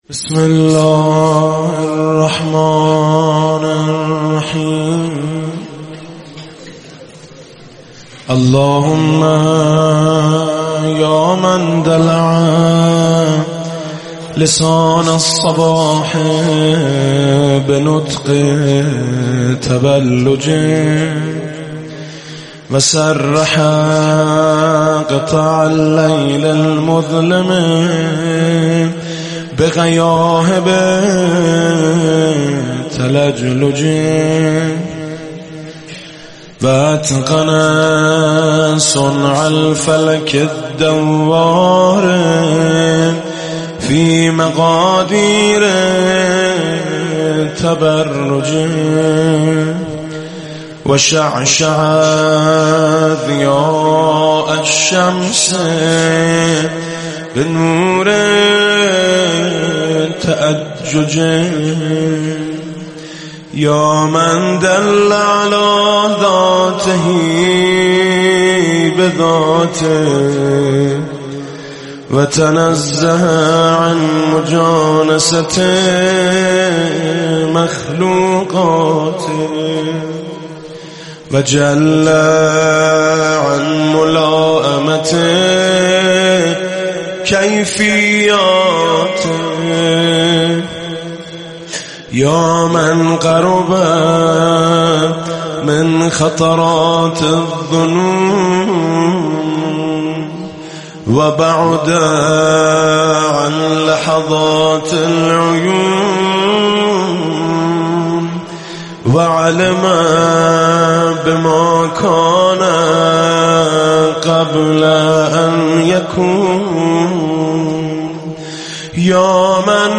صوت/ دعای"صباح"با نوای میثم مطیعی